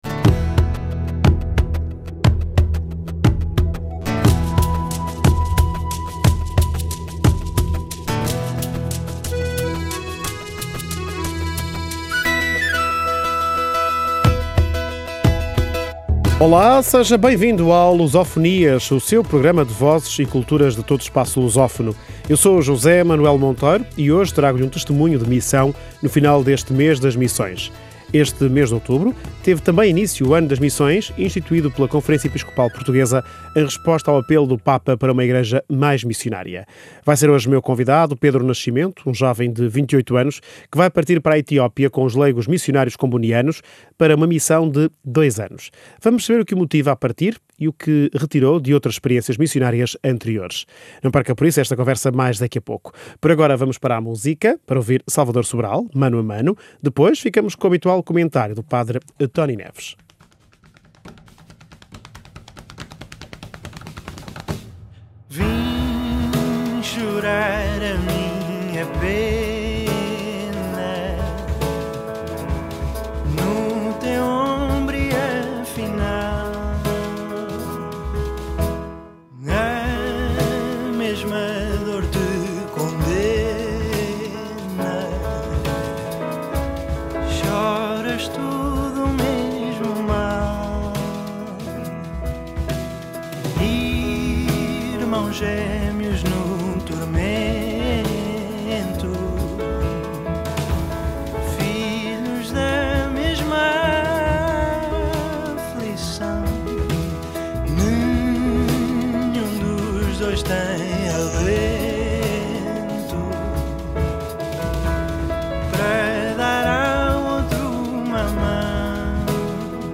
Este Luso Fonias é dedicado aos leigos em missão. A encerrar o mês de Outubro, Mês das Missões, e quando tem início o Ano Missionário convocado pela Conferência Episcopal Portuguesa, trazemos o testemunho de um jovem leigo que está prestes a partir numa missão de dois anos na Etiópia.